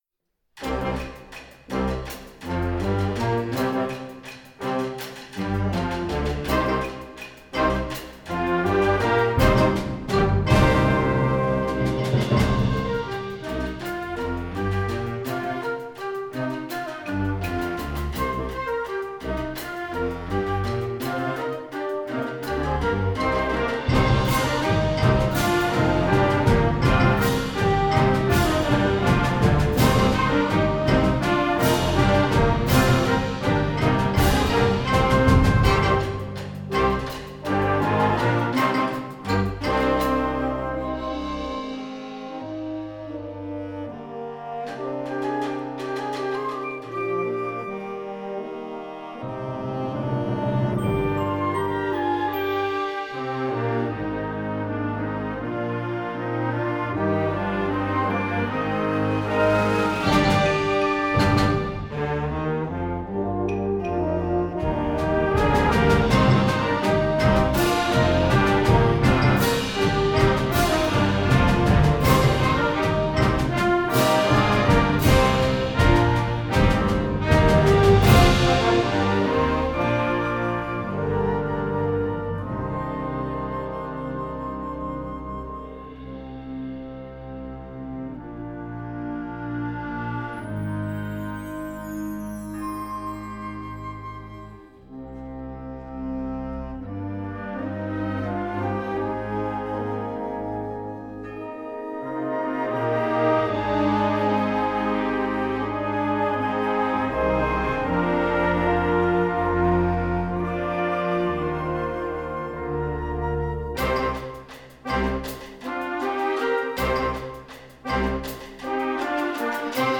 Gattung: Konzertwerk für Jugendblasorchester
Besetzung: Blasorchester
ist ein energiegeladenes Stück mit einem funkigen Groove